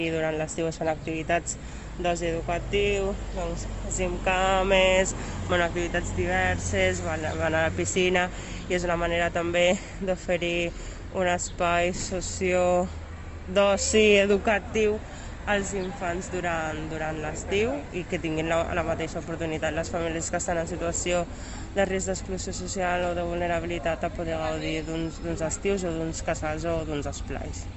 Tall de veu M.Sall
tall-de-veu-de-la-regidora-mariama-sall-sobre-el-servei-d2019intervencio-socioedicativa-mercadet-de-bordeta